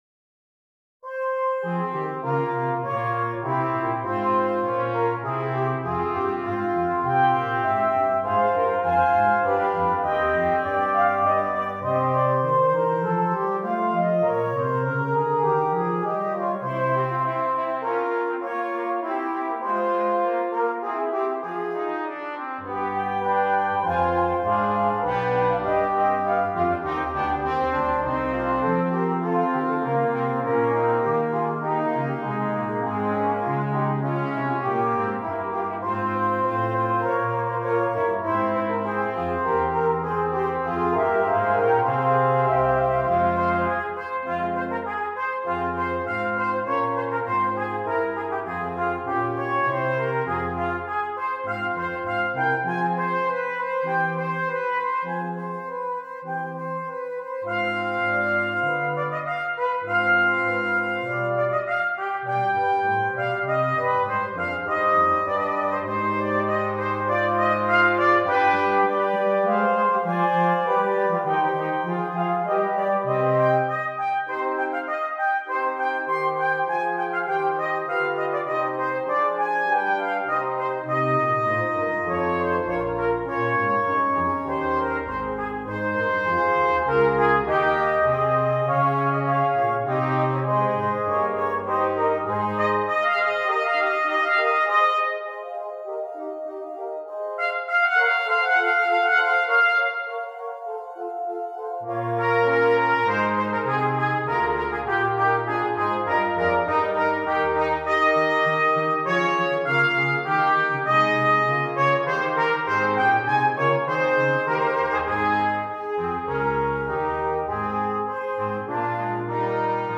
Brass Quintet and Solo Trumpet